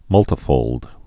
(mŭltə-fōld)